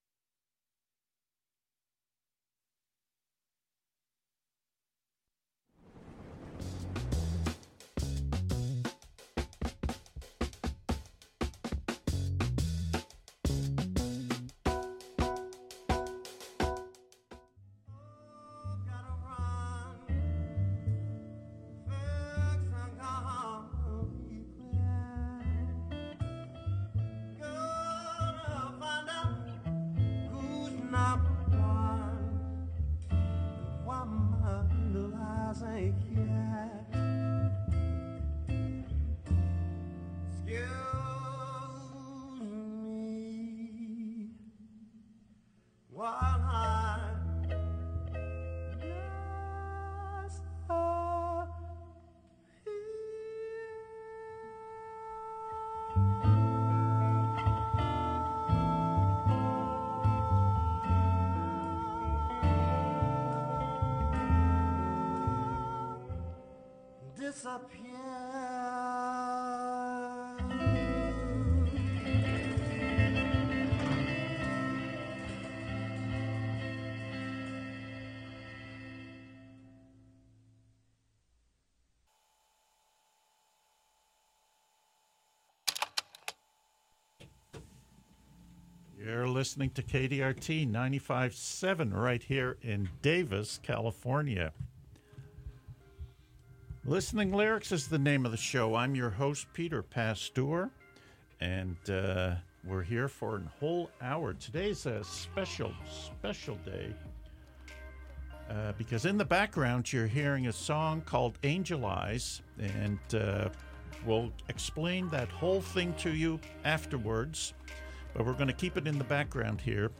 Right here on KDRT 95.7FM in Davis, California. Listening Lyrics is a genre free zone - we feature the artist.